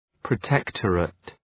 Προφορά
{prə’tektərıt}